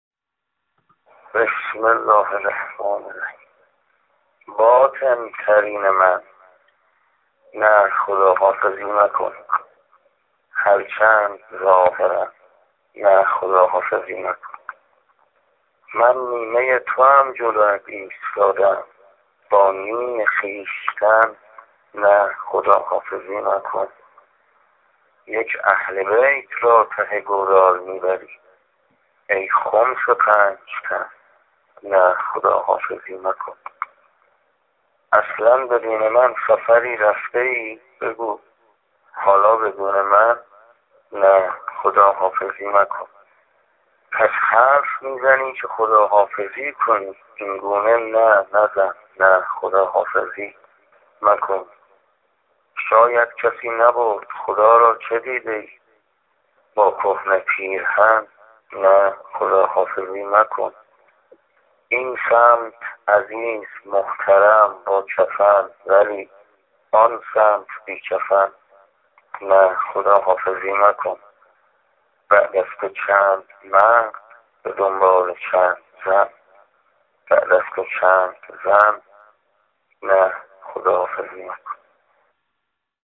صوت شعر خوانی شاعران آیینی در ایام محرم